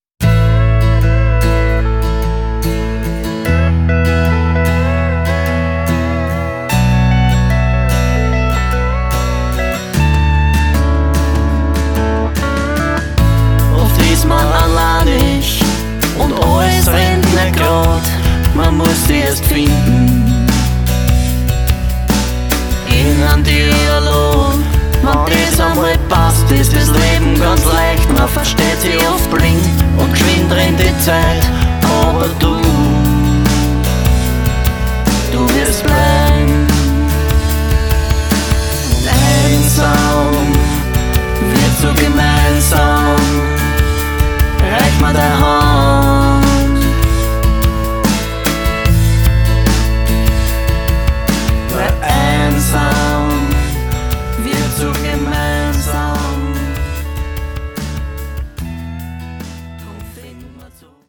Erfrischende Ballade mit einem Ticken Humor.